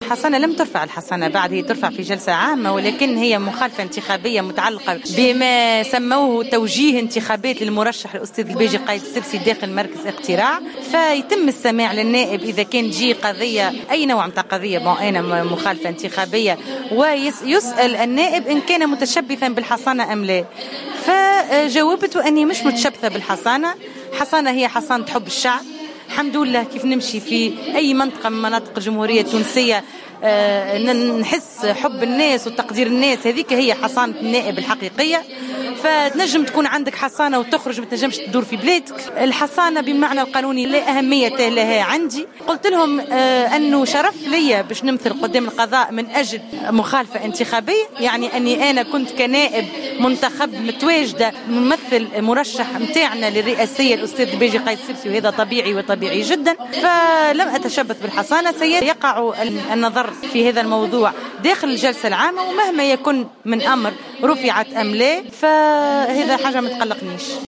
وأوضحت في تصريحات صحفية على هامش احدى اجتماعات نداء تونس في سوسة، أن الحصانة يتم رفعها بمقتضى جلسة عامة.